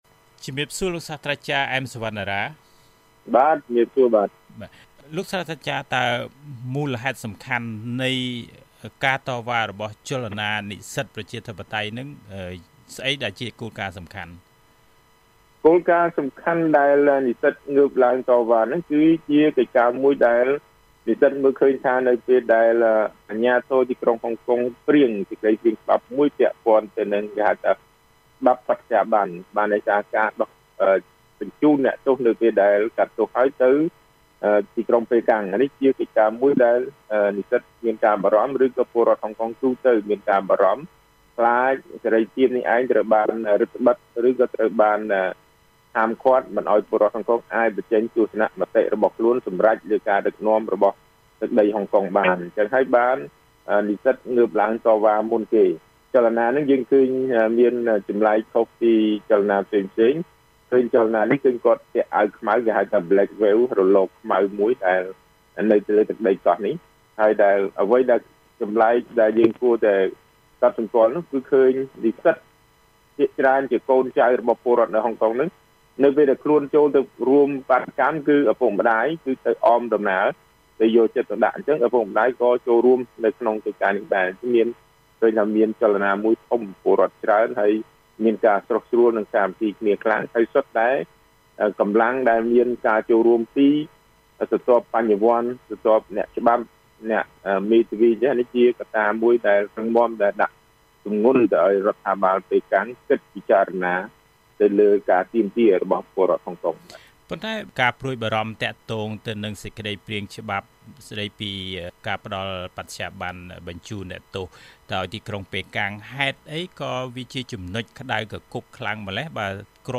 បទសម្ភាសន៍ VOA៖ ជោគជ័យរបស់ក្រុមបាតុករប្រជាធិបតេយ្យអាចជាមេរៀនសម្រាប់កម្ពុជា